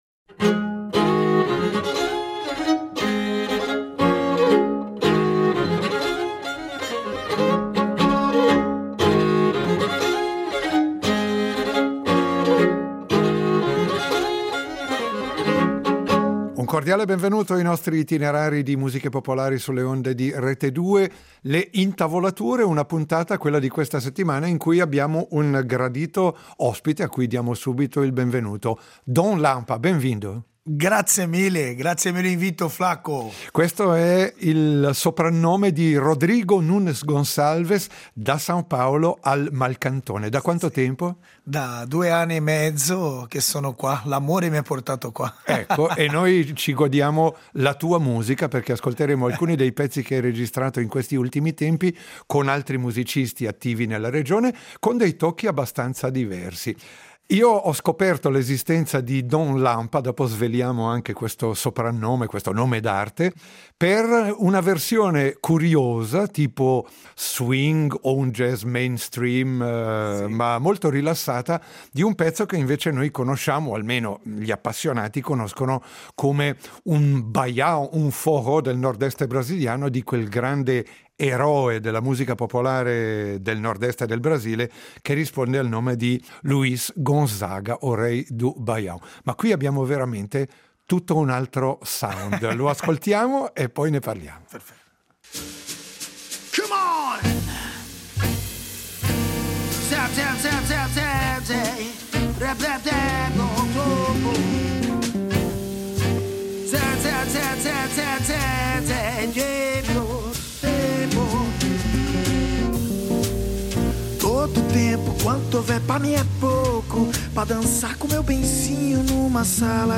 Ai nostri microfoni ci racconta delle sue ultime avventure sonore in compagnia di artisti di casa nostra, ma anche di classici brasiliani rivisitati con varietà di stile e grande originalità…